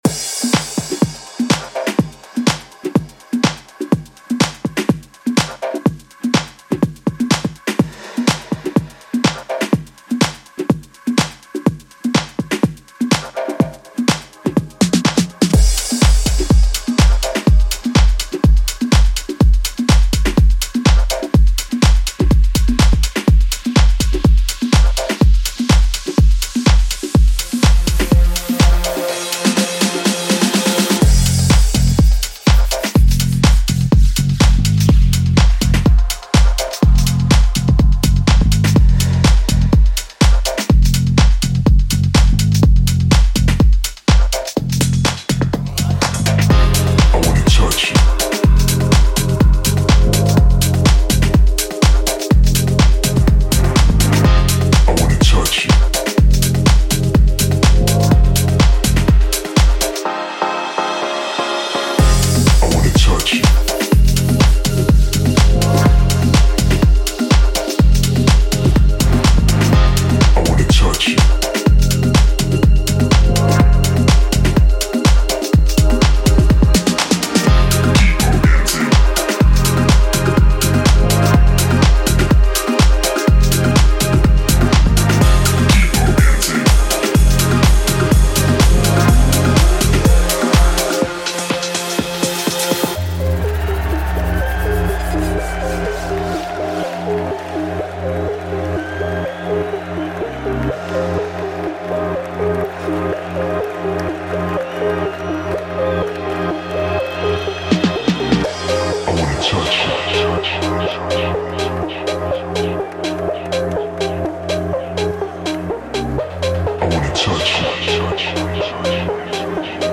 Warm, organic and perfect to fill an empty track.
Bass: bouncy and rich.